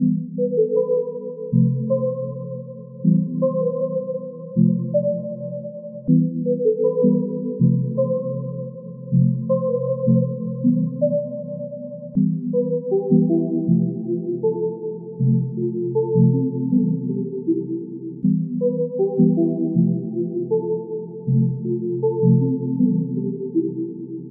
描述：波浪形奇纳合成器
Tag: 158 bpm Hip Hop Loops Pad Loops 4.09 MB wav Key : Unknown FL Studio